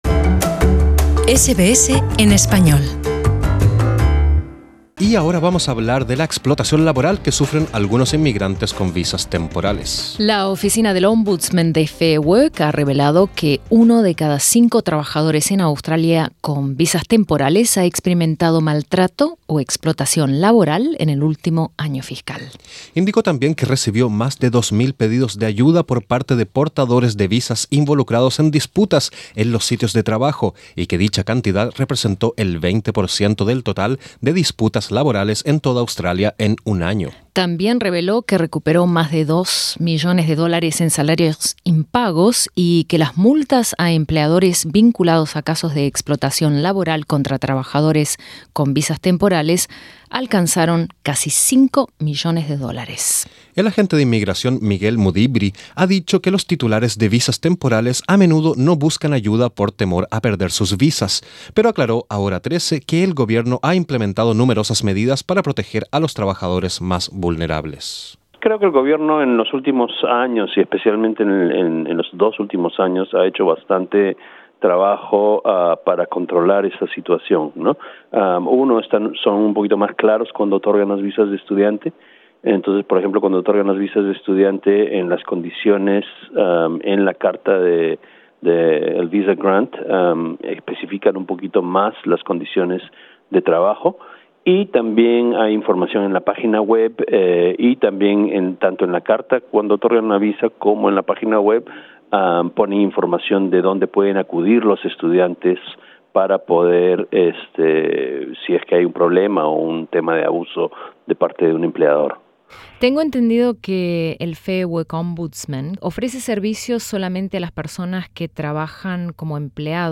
En entrevista con SBS Spanish